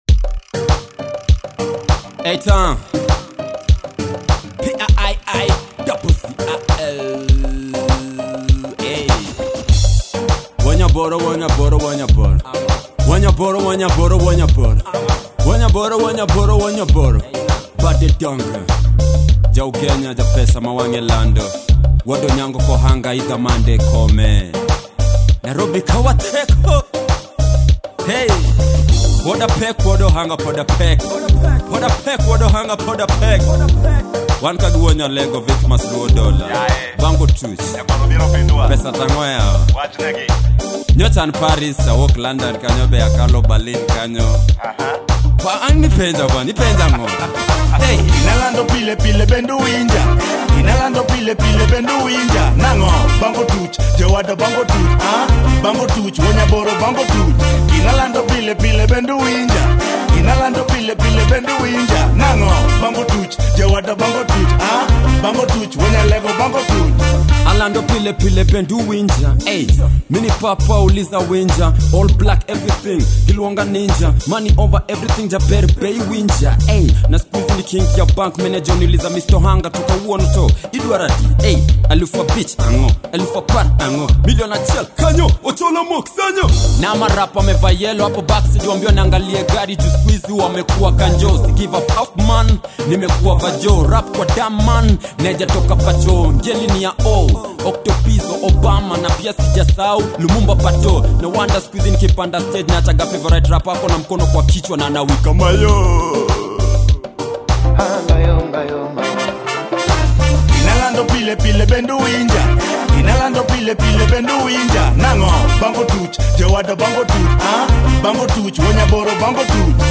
The delivery by both artists was perfect…